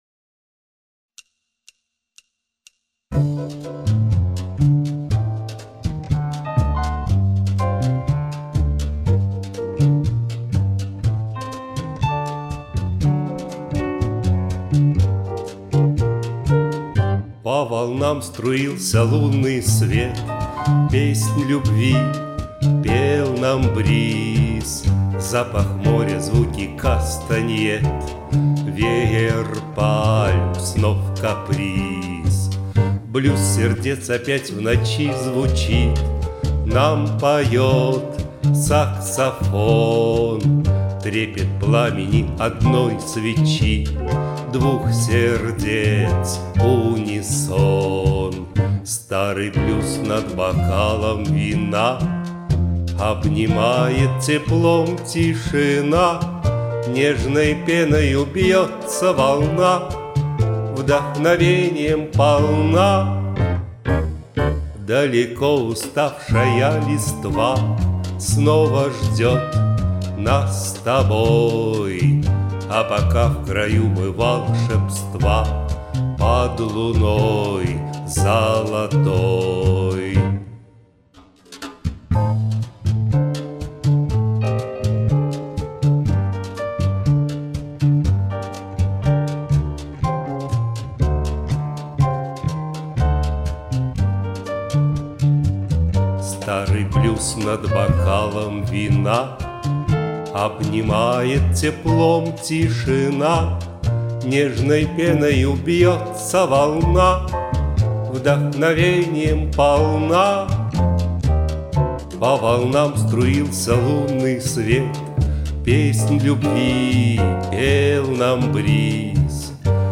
0.sway-blyuz-serdets (1).mp3